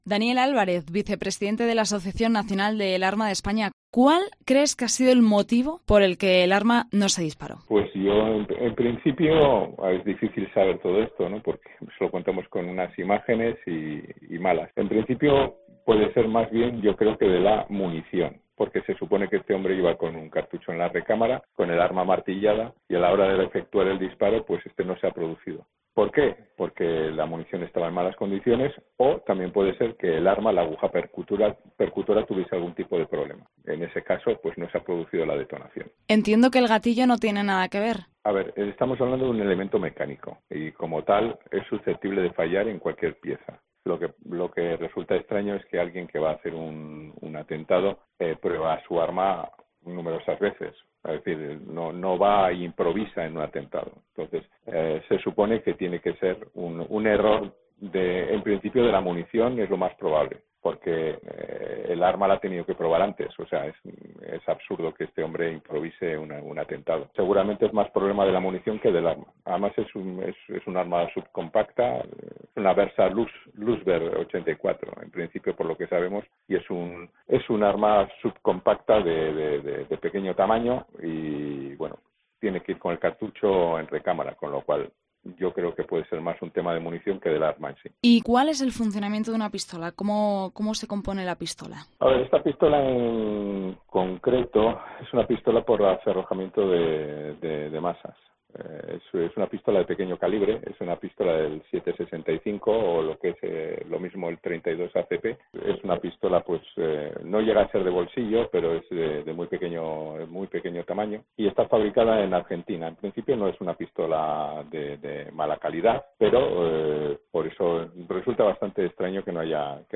Tras el intento de asesinato a la vicepresidenta de Argentina, COPE se ha puesto en contacto con un experto en armas para que dé más detalles de lo ocurrido